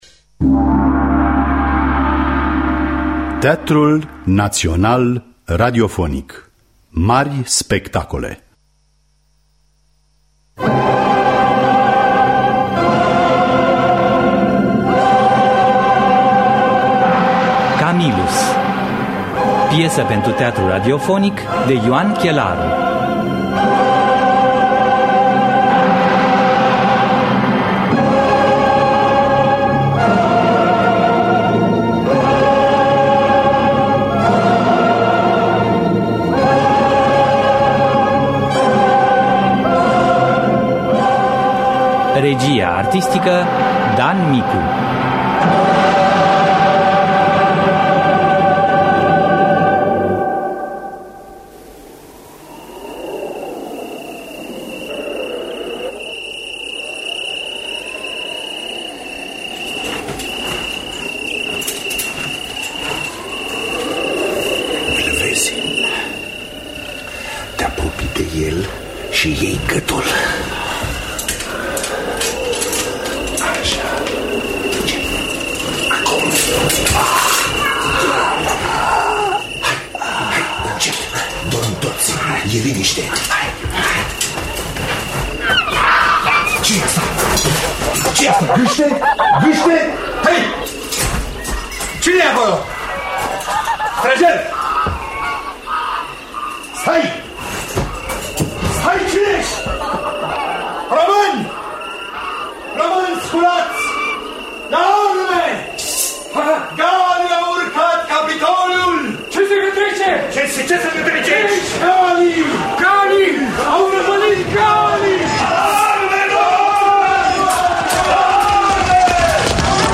Camillus de Ioan Chelaru – Teatru Radiofonic Online